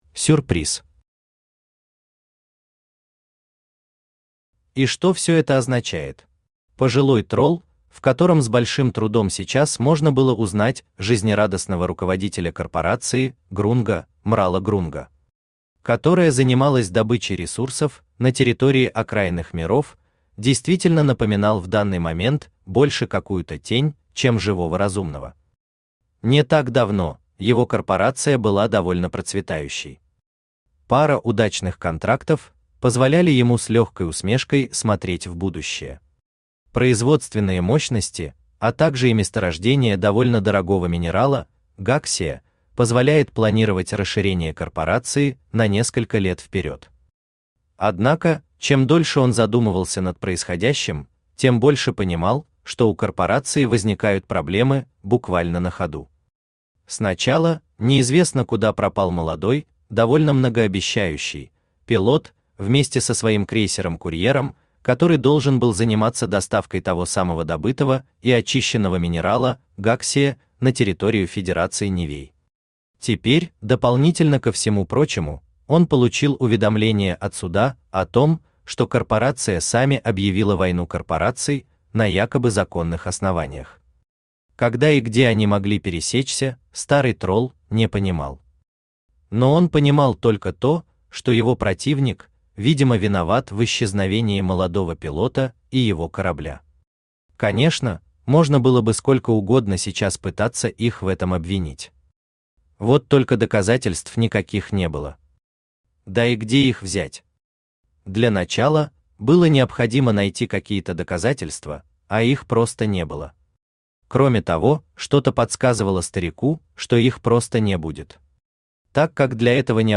Тень в тени Автор Хайдарали Усманов Читает аудиокнигу Авточтец ЛитРес.